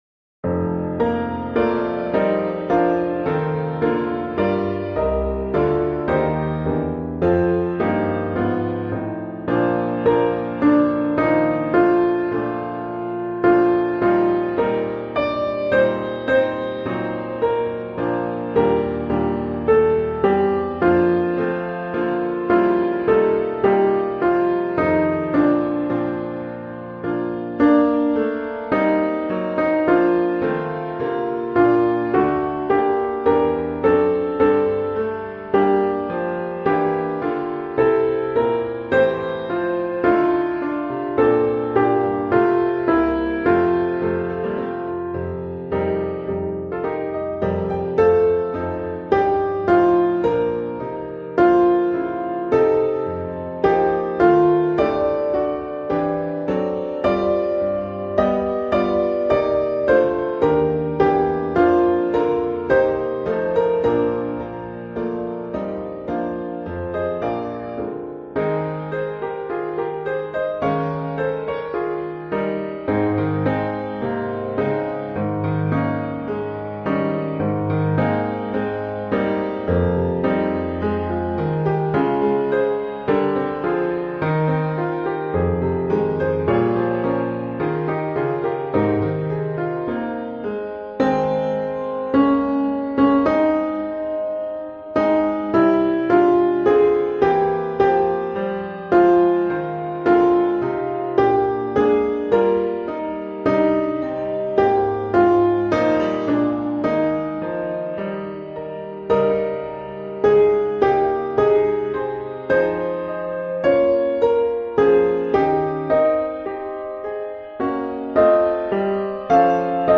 O Zion, Haste – Soprano
o-zion-haste-soprano